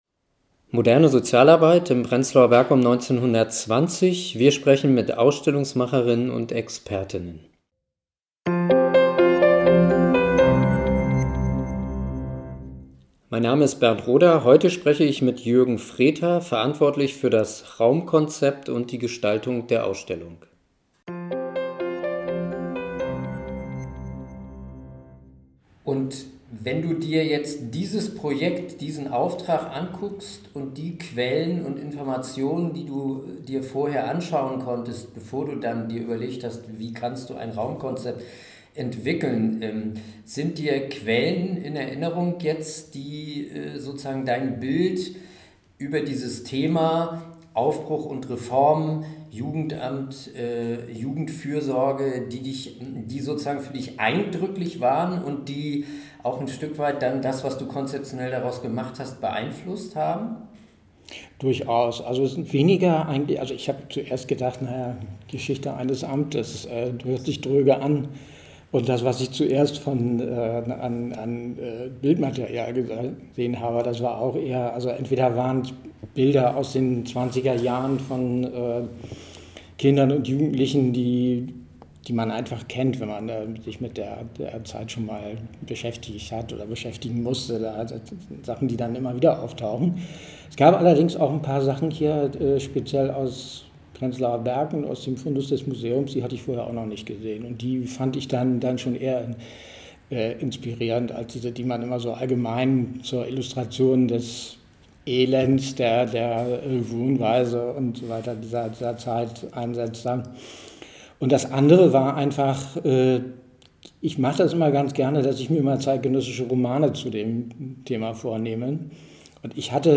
Interviews zum Thema: Aufbruch und Reformen – Pionierinnen und Pioniere der modernen Sozialarbeit in Prenzlauer Berg während der Weimarer Republik | Teil 2
Moderne Sozialarbeit in Prenzlauer Berg um 1920 – wir sprechen mit Ausstellungsmacher_innen und Expertinnen und Experten!